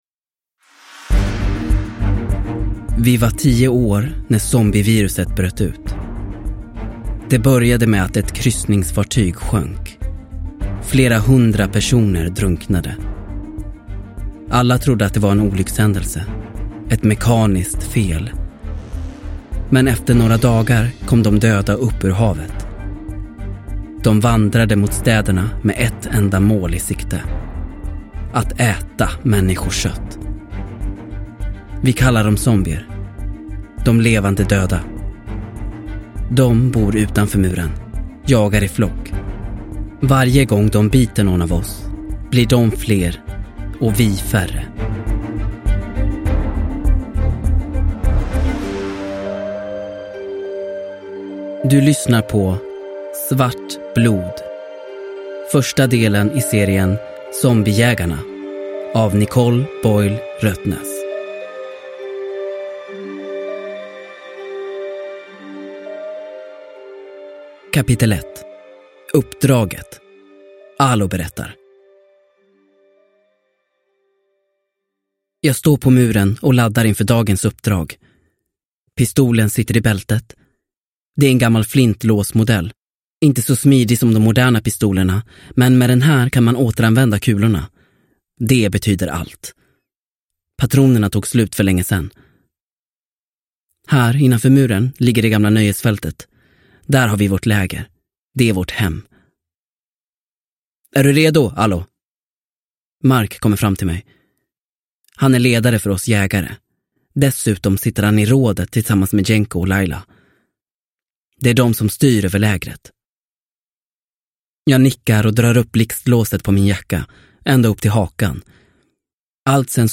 Svart blod – Ljudbok – Laddas ner